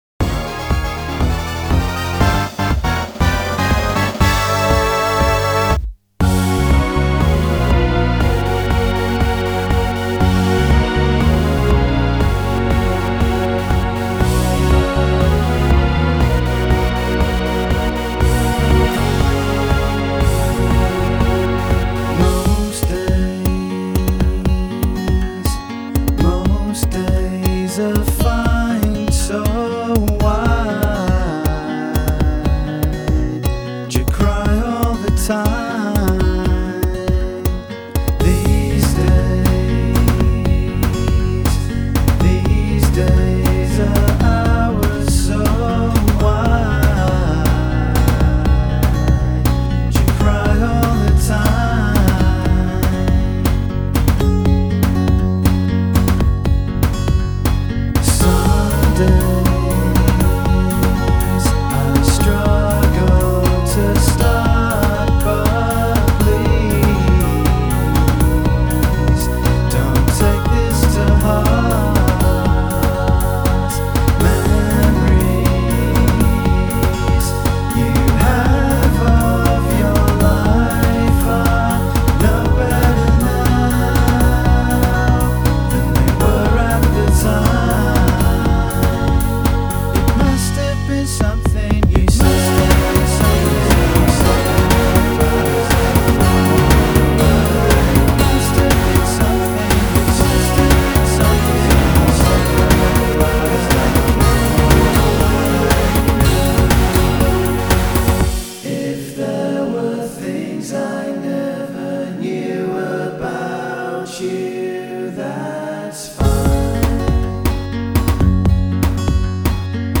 * Cover *